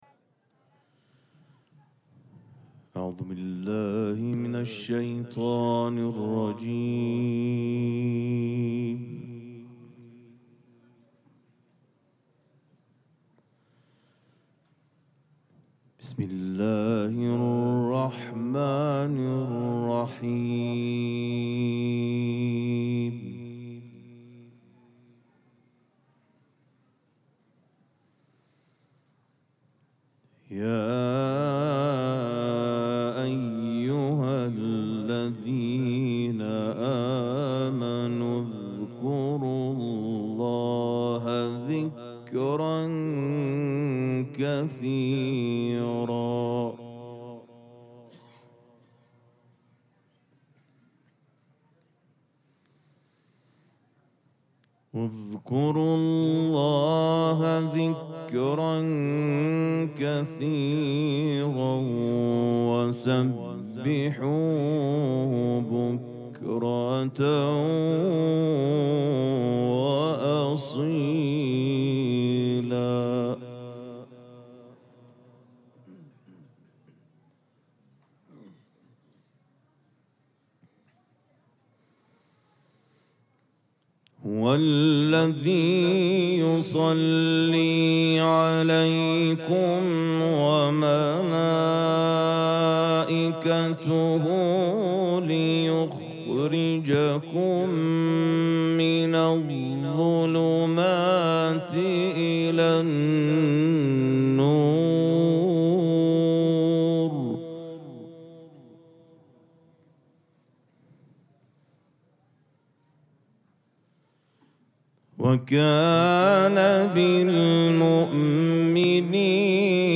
شب اول مراسم جشن ولادت سرداران کربلا
حسینیه ریحانه الحسین سلام الله علیها
قرائت قرآن